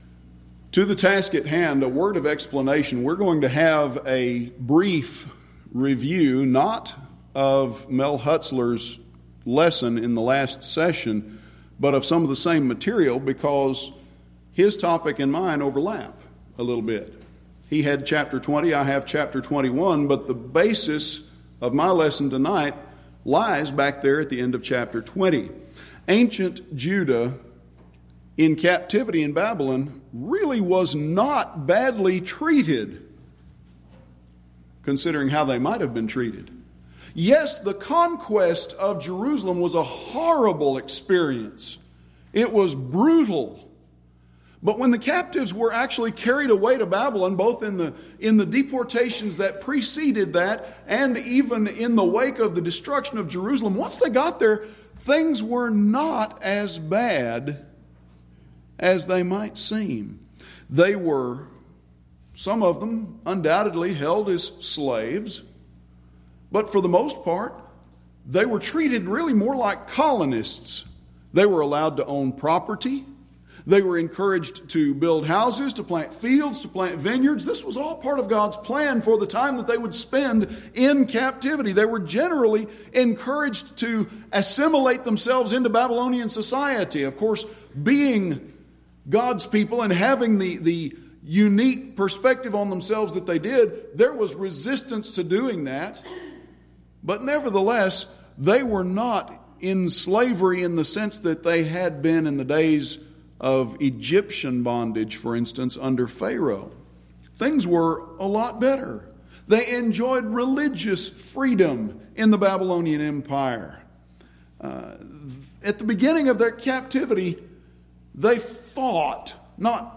Event: 10th Annual Schertz Lectures
lecture